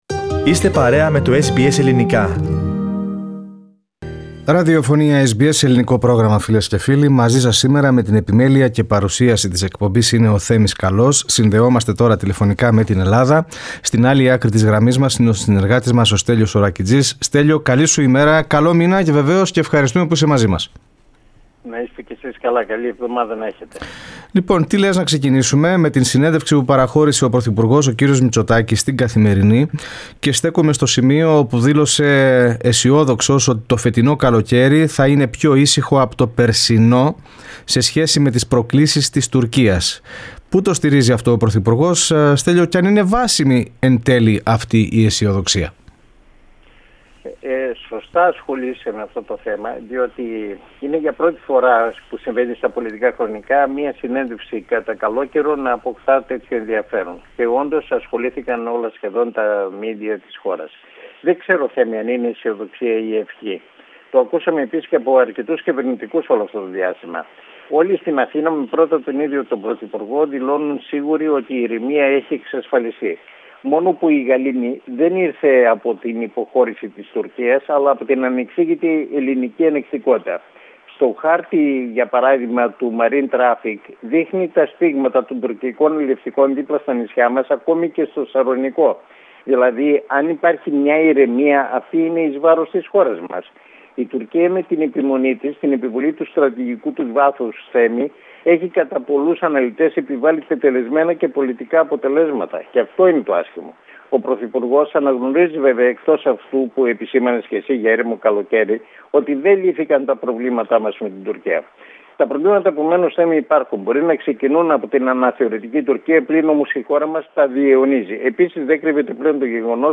Την απόφαση της κυβέρνησης να μην προχωρήσει σε νέο lockdown, αλλά σε μέτρα περιορισμού (αν χρειαστεί) που θα αφορούν μόνο όσους δεν θα έχουν κάνει το εμβόλιο, επιβεβαίωσε ο πρωθυπουργός κ. Κυριάκος Μητσοτάκης σε συνέντευξή του.